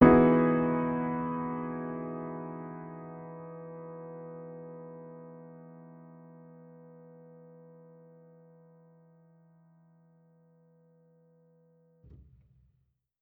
Index of /musicradar/jazz-keys-samples/Chord Hits/Acoustic Piano 2
JK_AcPiano2_Chord-Emaj9.wav